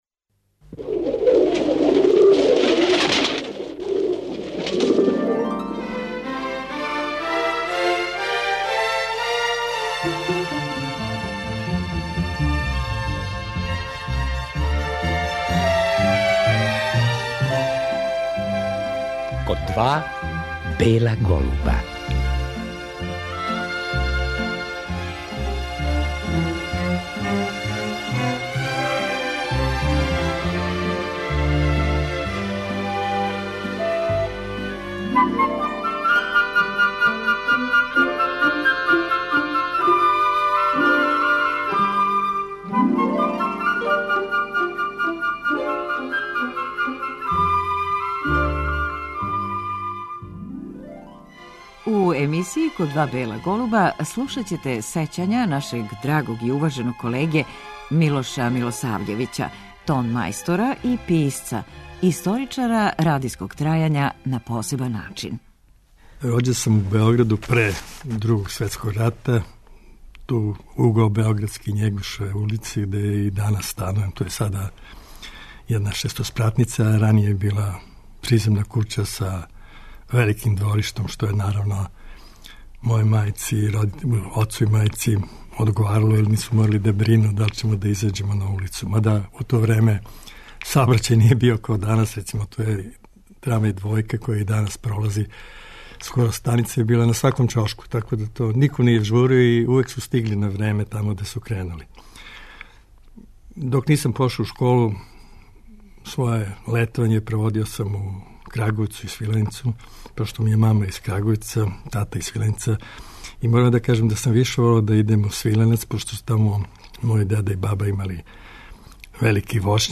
Много тога је забележио на магнетофонској траци а ми ћемо слушати како су неке од анегдота казивали ствараоци програма.